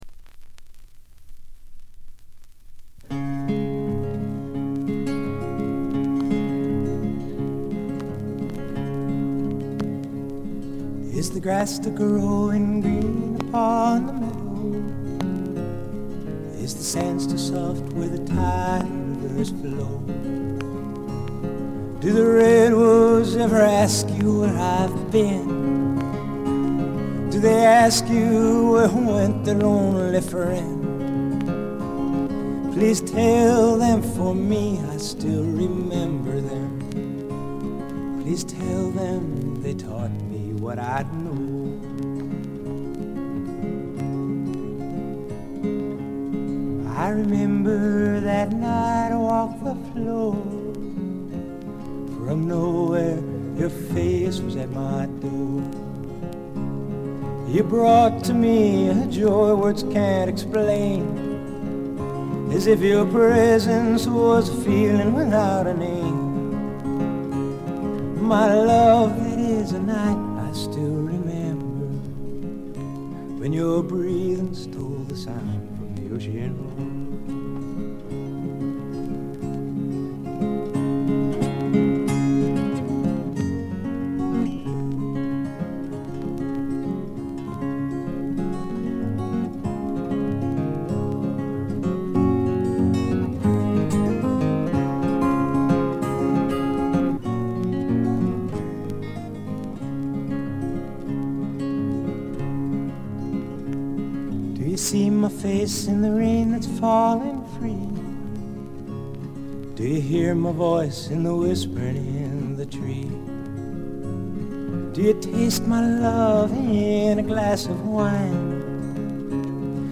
バックグラウンドノイズ、チリプチ多め大きめ。プツ音、周回気味のノイズも。
2曲でセカンド・ギターが付くほかはすべて自身の弾き語りというとてもシンプルなものです。
米国フォーク／シンガーソングライターの基本。
試聴曲は現品からの取り込み音源です。
lead vocals, guitar, harmonica